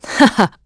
Isolet-Vox_Happy2.wav